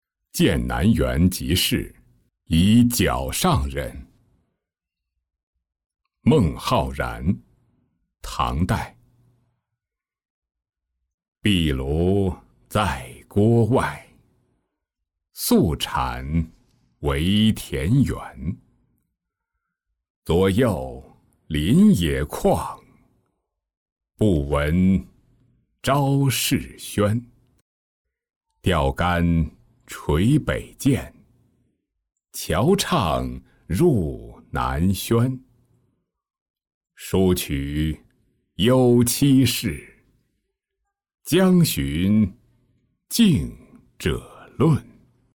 涧南园即事贻皎上人-音频朗读